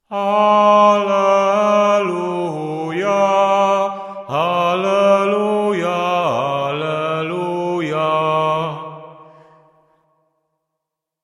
Мужчина исполняет Аллилуия с эффектом эха